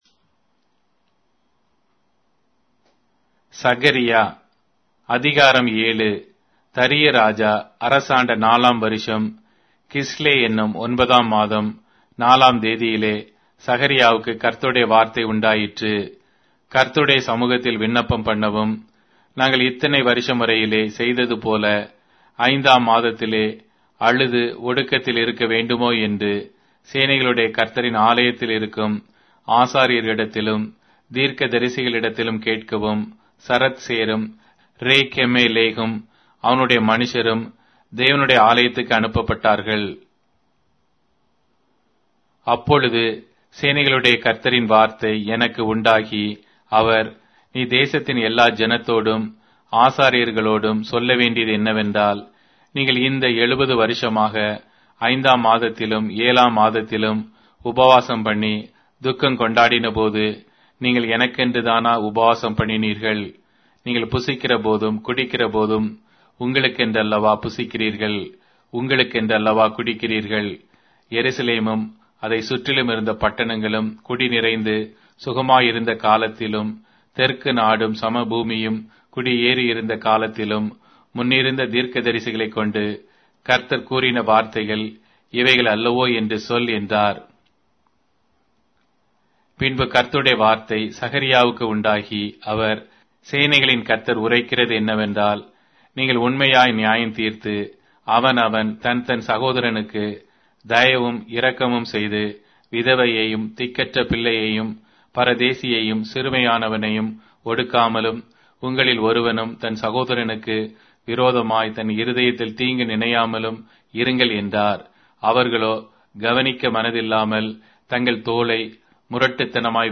Tamil Audio Bible - Zechariah 12 in Esv bible version